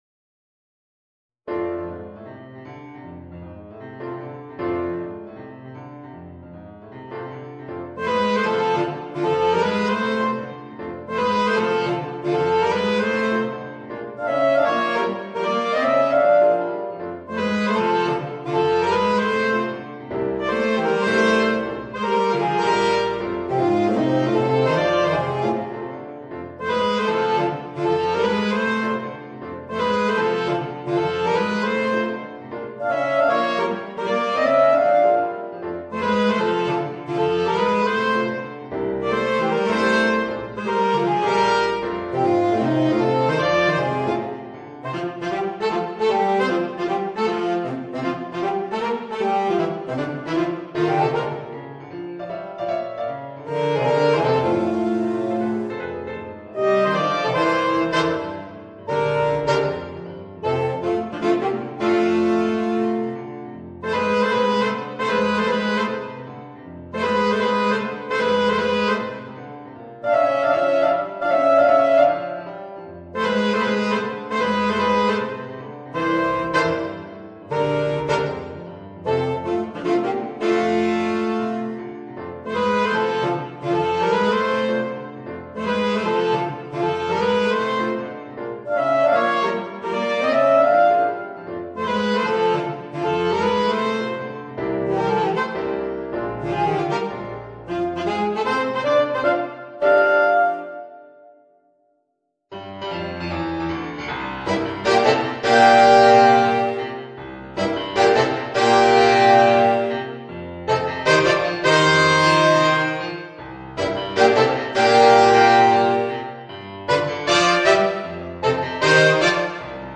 Voicing: Alto Saxophone, Tenor Saxophone w/ Audio